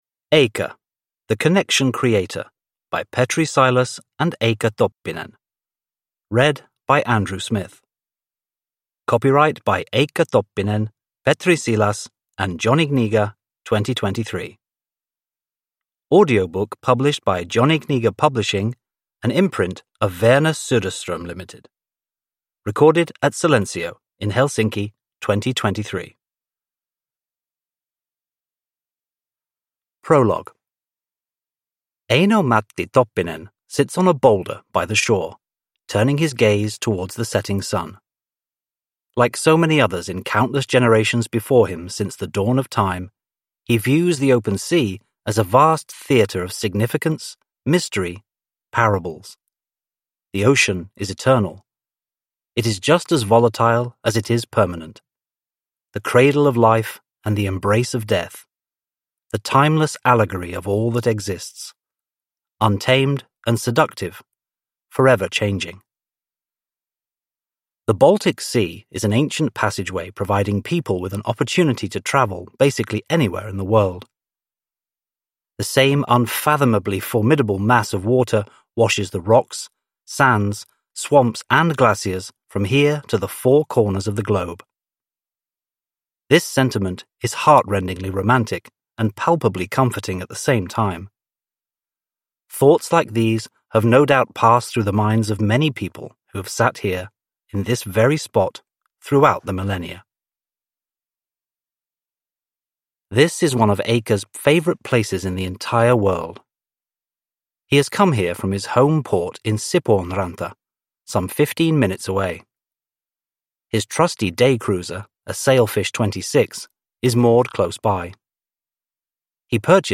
Eicca – The Connection Creator – Ljudbok – Laddas ner